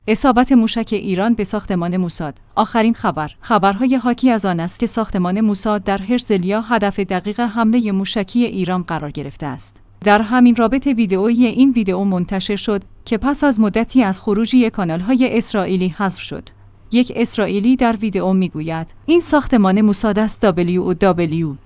یک اسرائیلی در ویدئو می گوید: این ساختمان موساد است WOW!